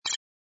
sfx_ui_react_data02.wav